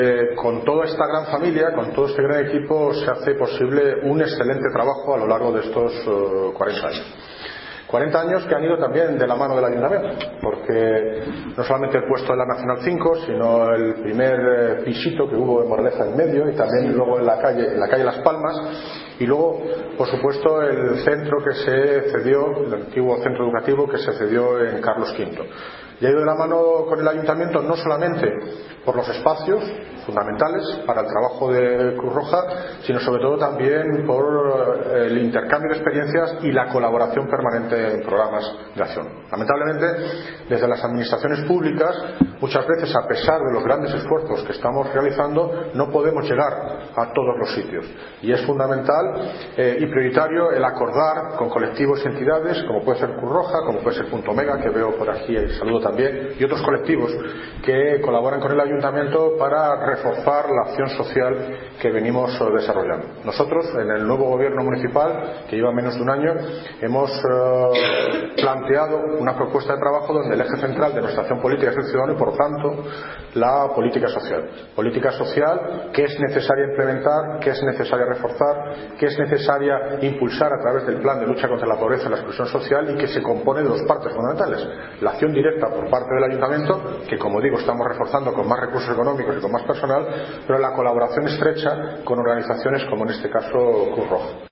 Audio - David Lucas (Alcalde de Móstoles) Sobre Exposición 40 años de Cruz Roja en Móstoles